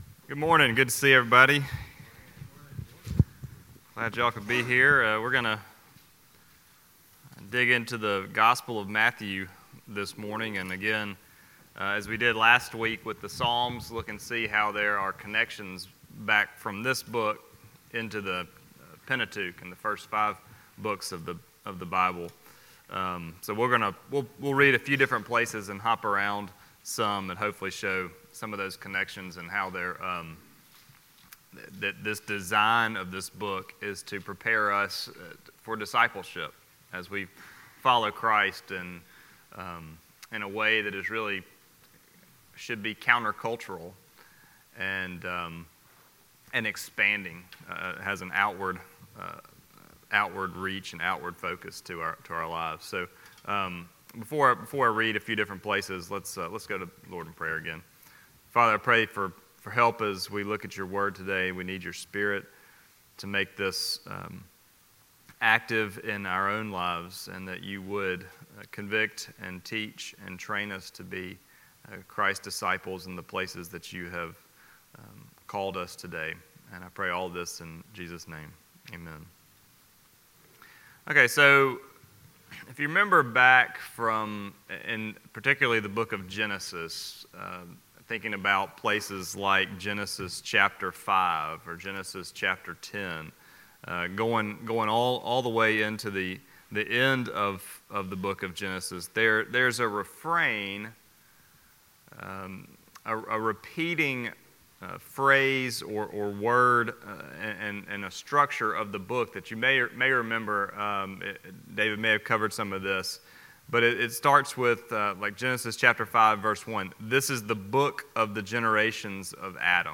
Tuesday Men's Breakfast Series